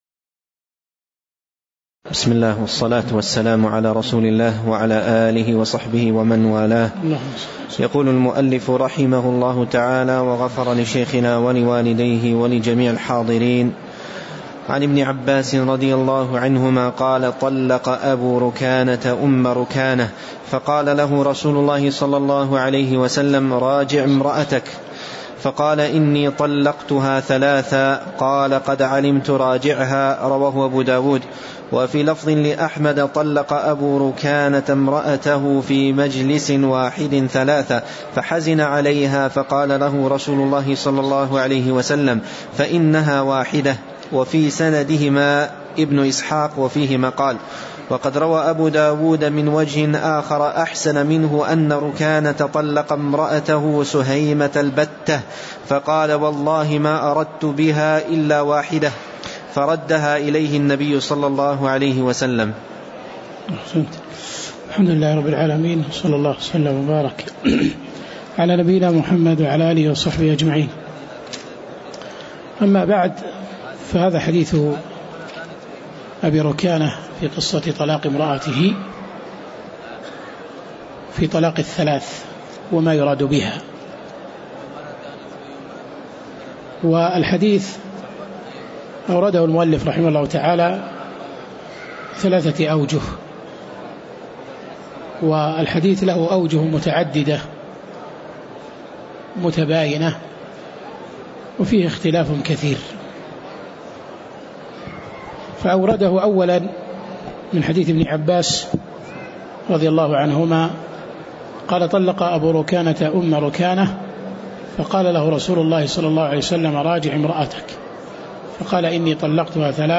تاريخ النشر ١٧ جمادى الأولى ١٤٣٨ هـ المكان: المسجد النبوي الشيخ